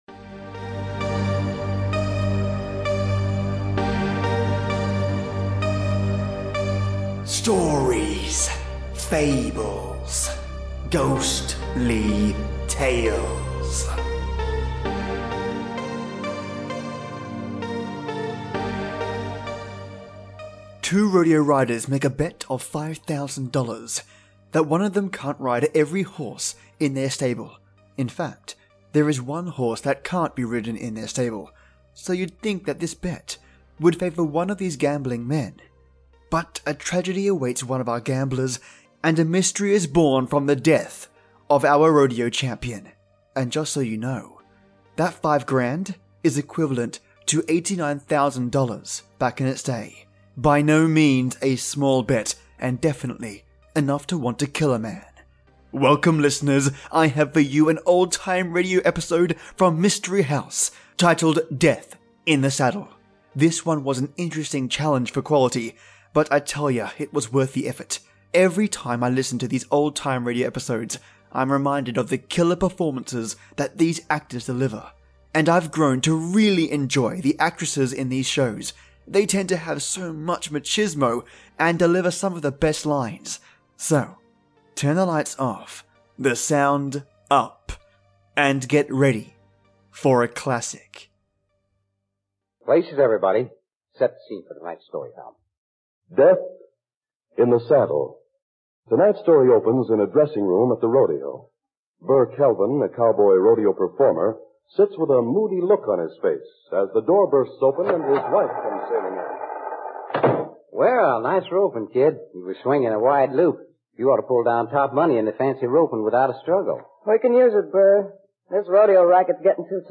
Episode 380 - SFGT | Death in the Saddle "MYSTERY HOUSE" Old Time Radio [Remastered]